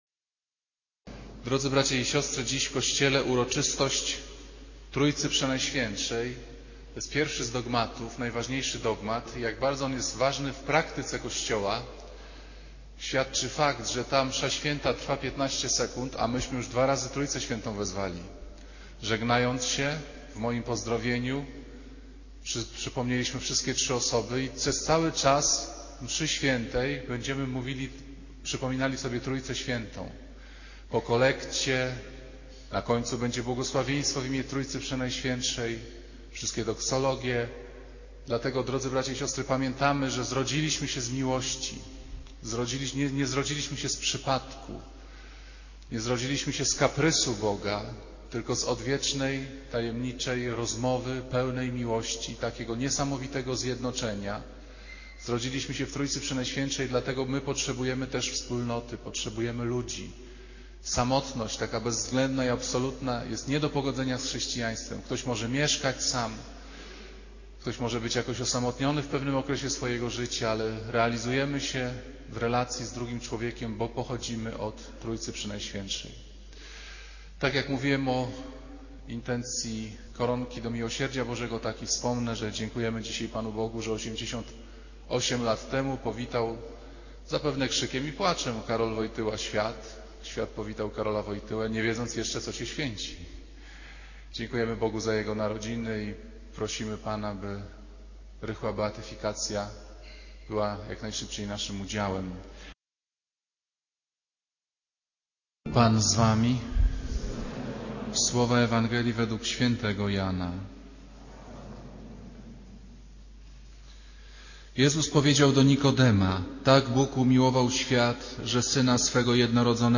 Kazanie z 18 maja 2008r.
niedziela, godzina 15:00, kościół św. Anny w Warszawie « Kazanie z 11 maja 2008r.